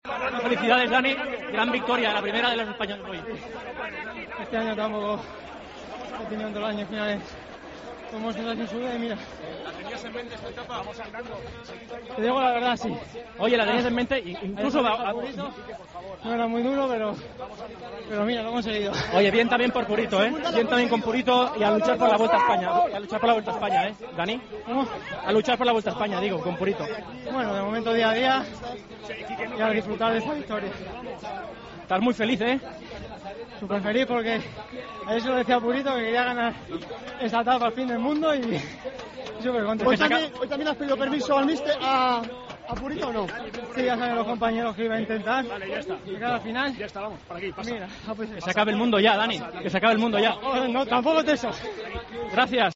El ciclista del Katusha, vencedor de la 4ª etapa de la Vuelta a España, nos cuenta sus impresiones en la meta de llegada.